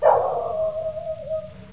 od_dogs1.wav